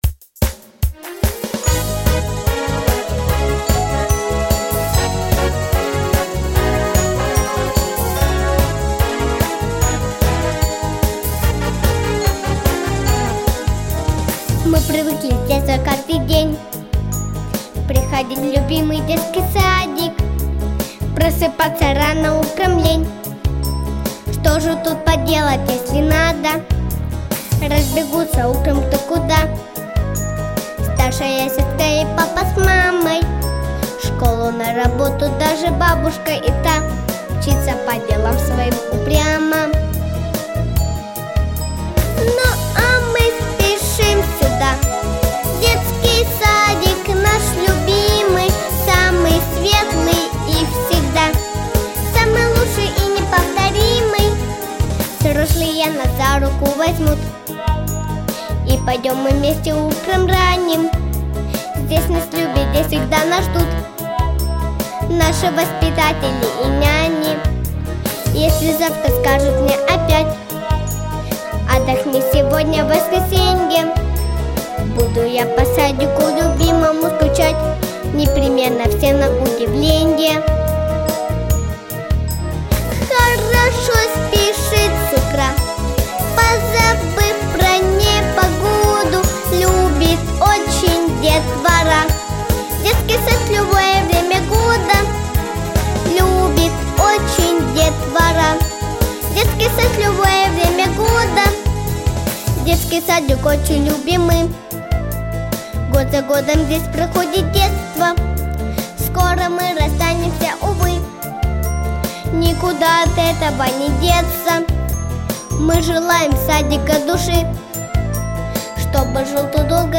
Песня для выпускного